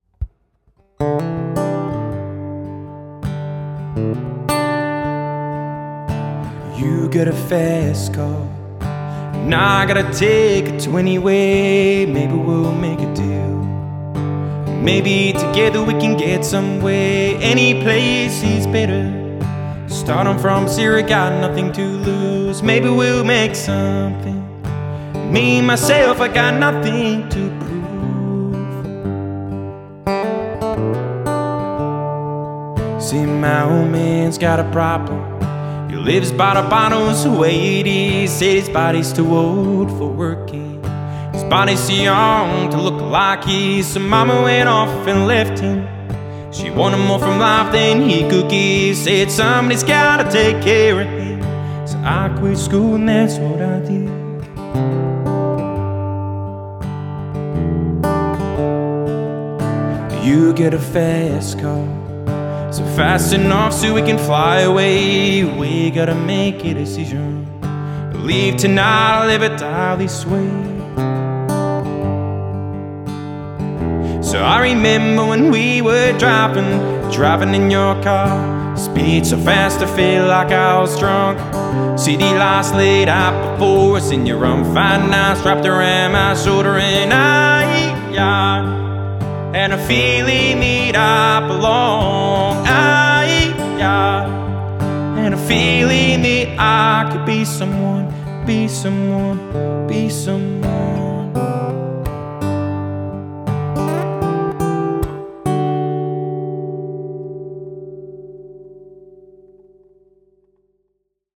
Vocal | Guitar | Looping | DJ